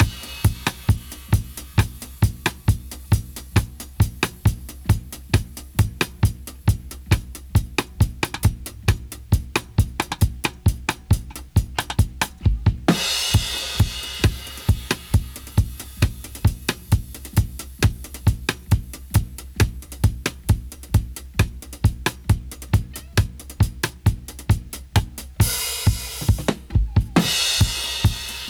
131-DRY-03.wav